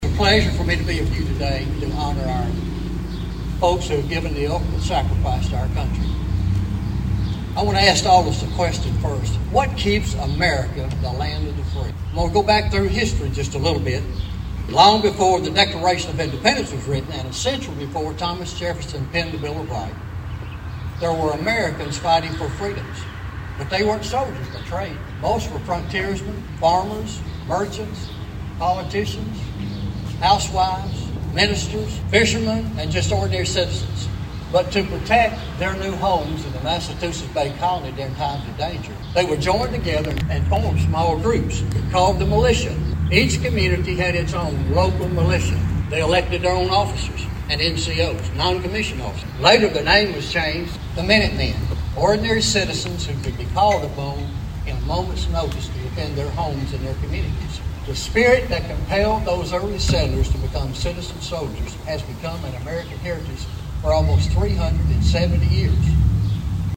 Weakley County Hosts Memorial Day Ceremony
The Weakley County Veteran’s Affairs Office hosted their annual Memorial Day ceremony on Monday outside the courthouse in Dresden.